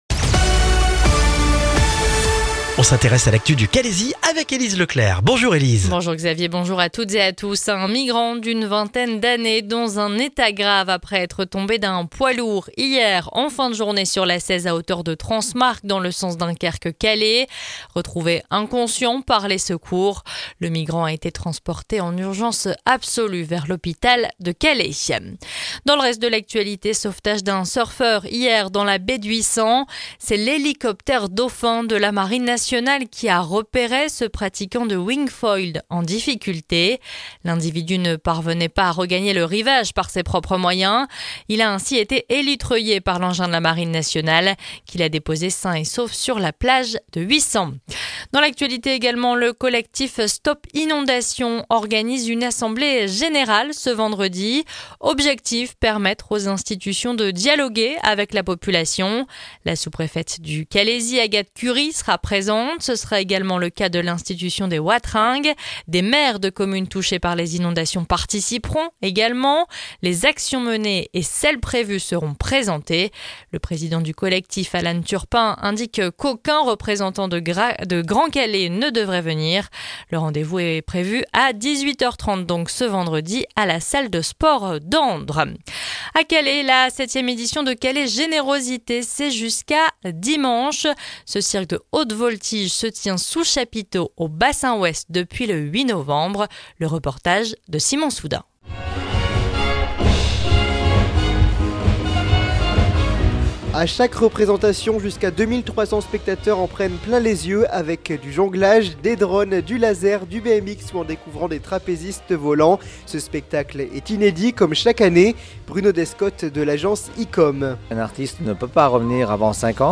Le journal du mercredi 13 novembre dans le Calaisis